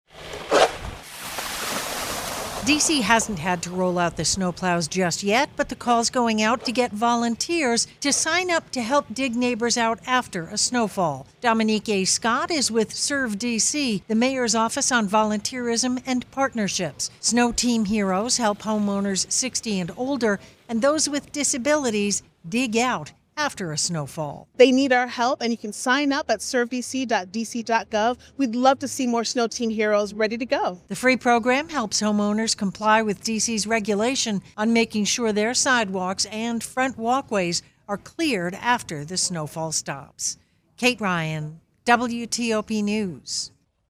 reports on D.C.'s effort to get volunteers helping their neighbors with snow